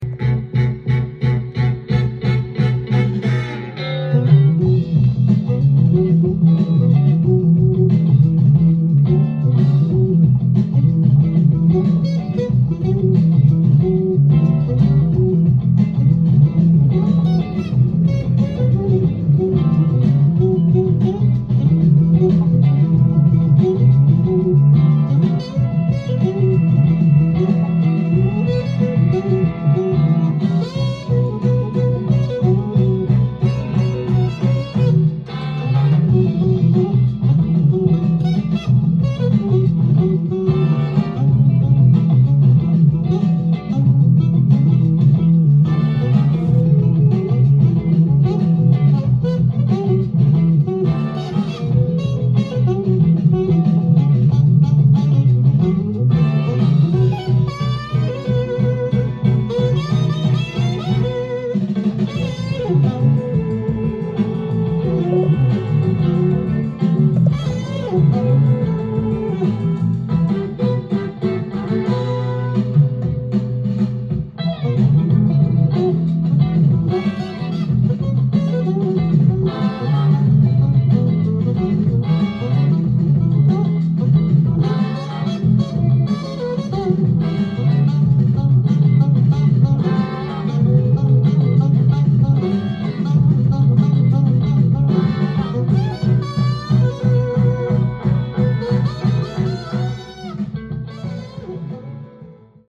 店頭で録音した音源の為、多少の外部音や音質の悪さはございますが、サンプルとしてご視聴ください。
独特の音色と幅広い音楽性を持つサックス奏者